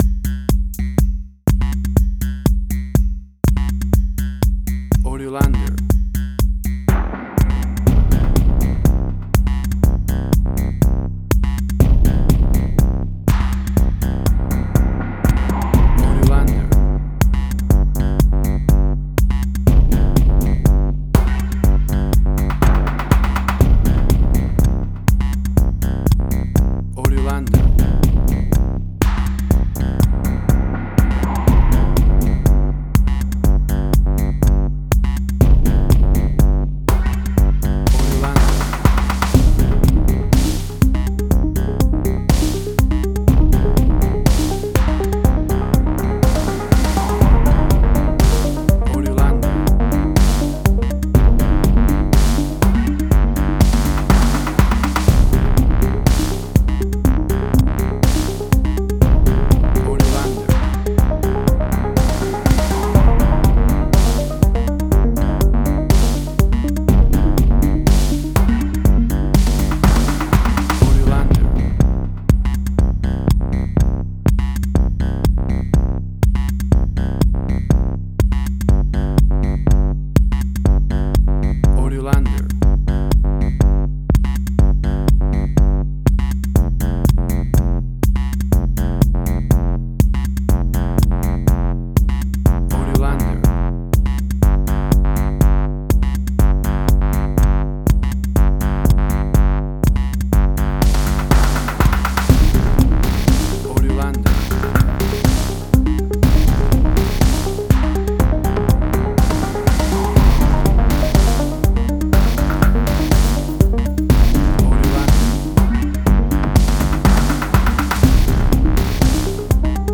Modern Science Fiction Film, Similar Tron, Legacy Oblivion.
WAV Sample Rate: 16-Bit stereo, 44.1 kHz
Tempo (BPM): 122